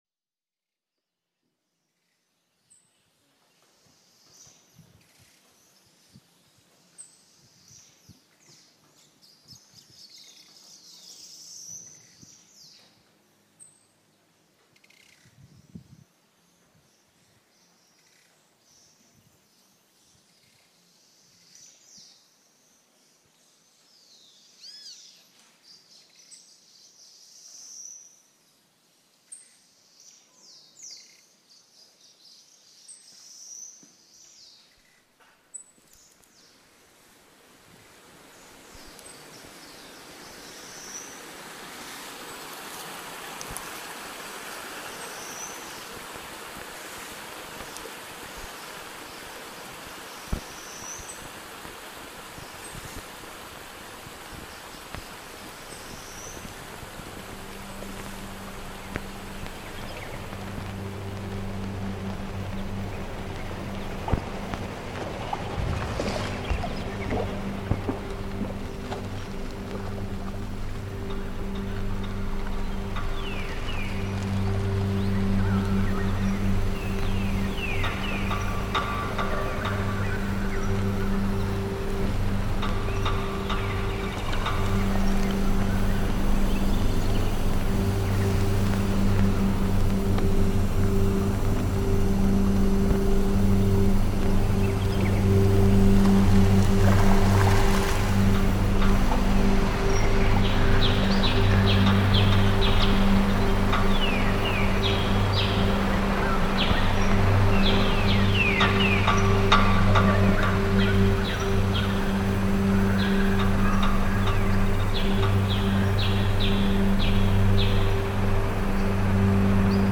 Genre: Ambient/Drone/Field Recordings.